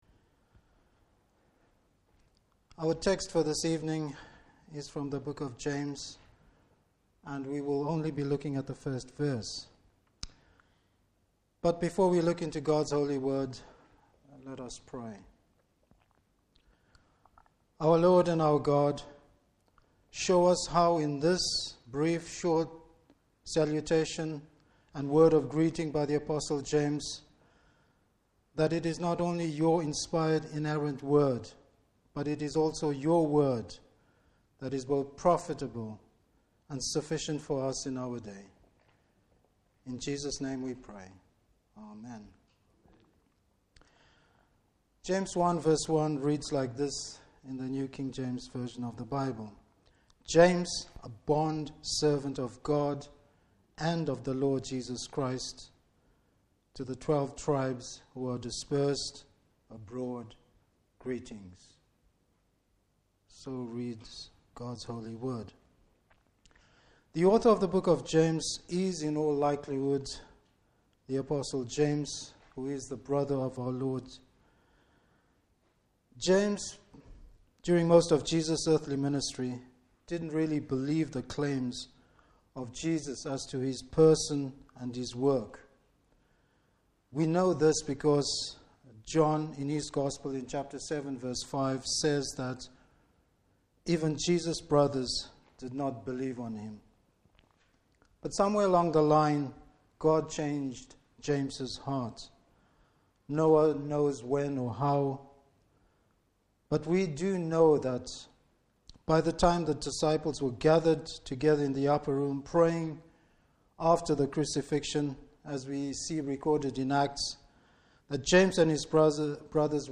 Service Type: Evening Service True servanthood.